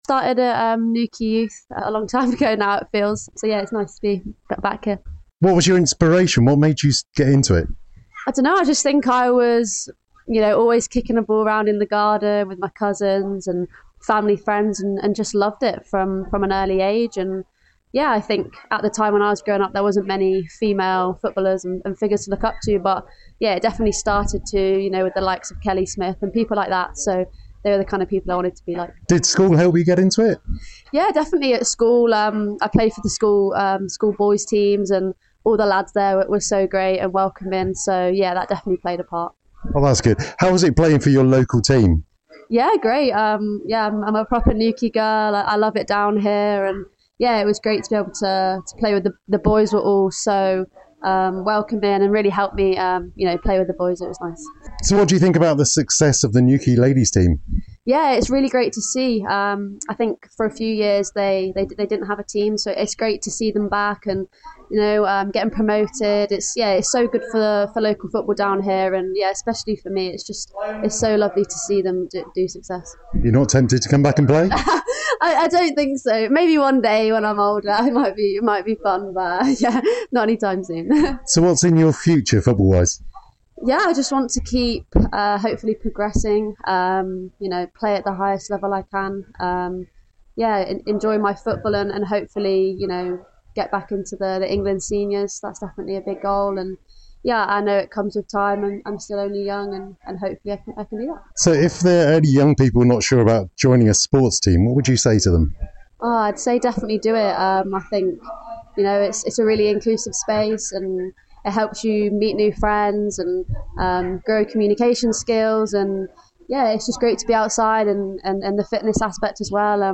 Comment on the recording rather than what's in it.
Speaking to Radio Newquay during a visit back to her hometown, the former Peppermints and Godolphin player said she’s staying focused on what’s ahead as she continues her career at the top level.